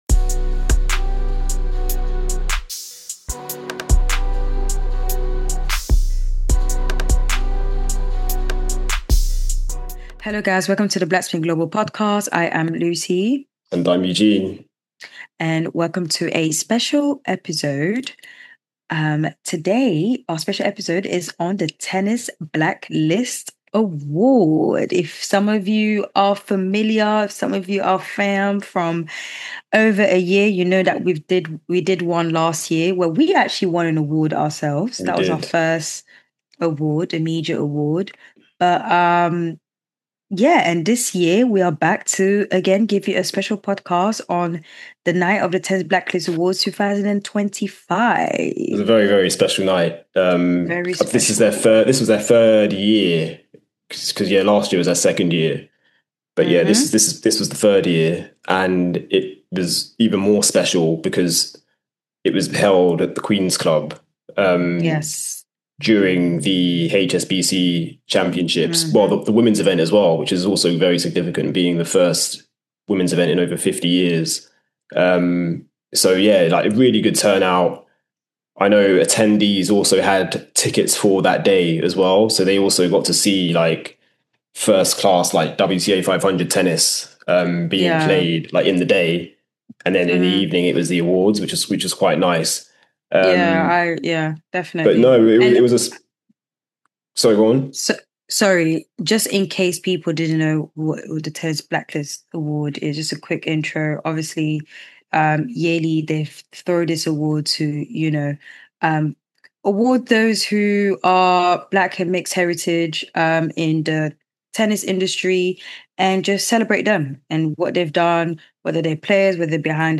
This year’s event was held at the prestigious Queen’s Club in west London during the women’s WTA 500 event.